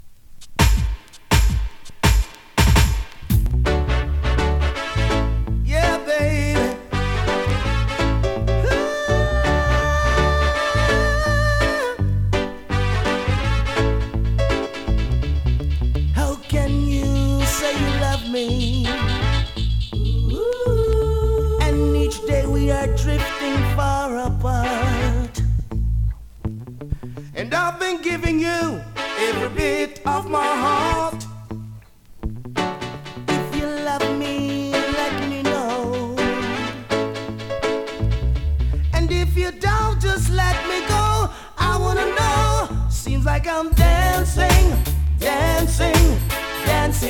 DANCEHALL!!
スリキズ、ノイズかなり少なめの